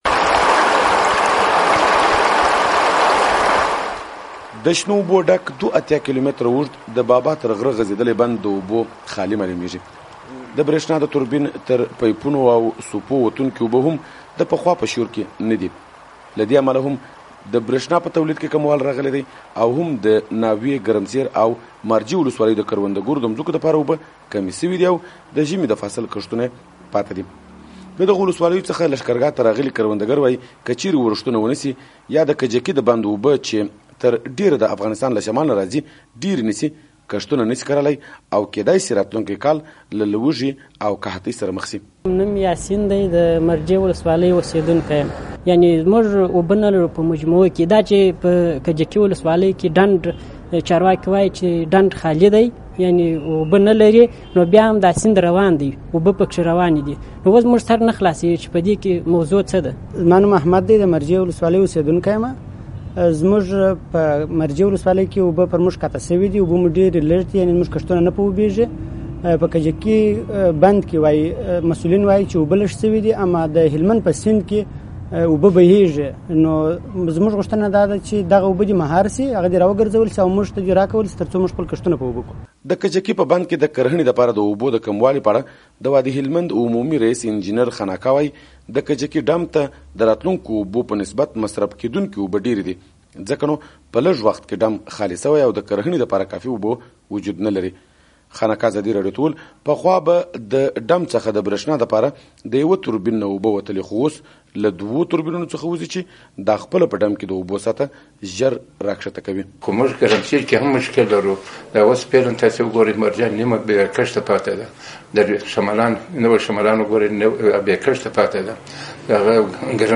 د هلمند راپور